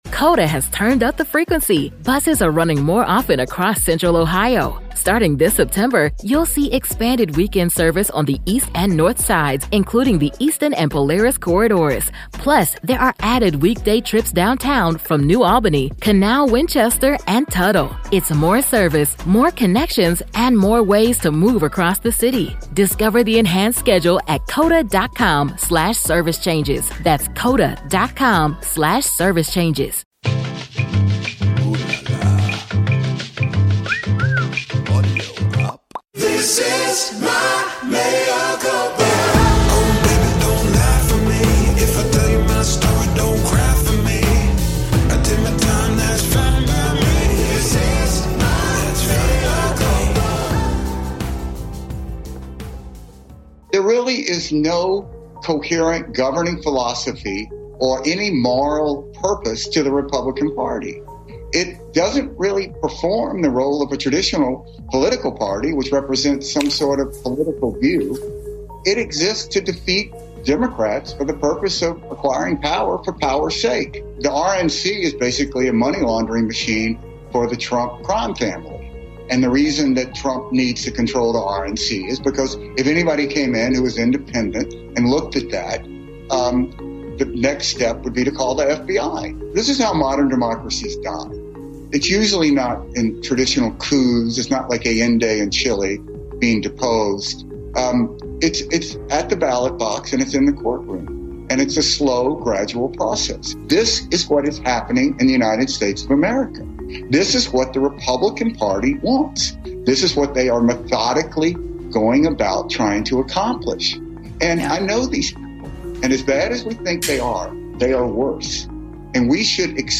How the GOP Went Insane + A Conversation with the Young Turks’ Cenk Uygur
The Young Turks' Cenk Uygur joins Mea Culpa to help Michael predict what will happen next.